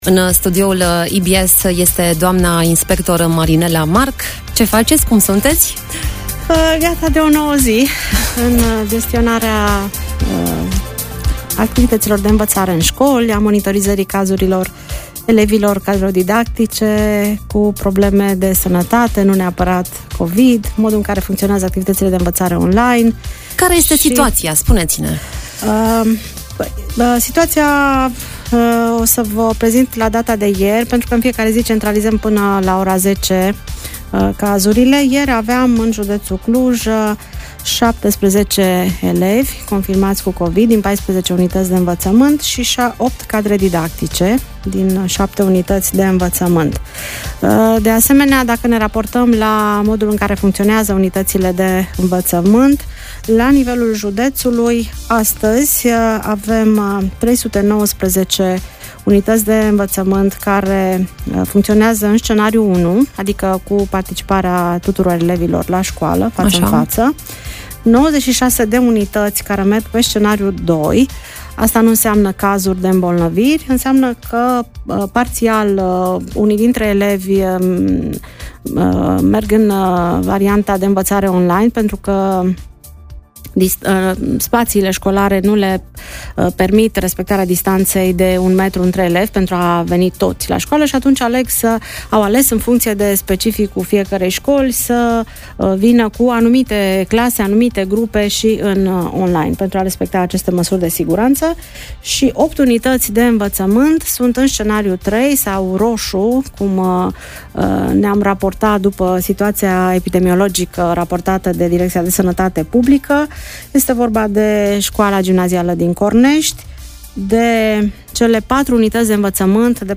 Inspectorul școlar general al județului Cluj, Marinela Marc, a spus la EBS Radio că există școli, în special în mediul rural, care sunt încă nepregătite de un scenariu care implică învățământ online.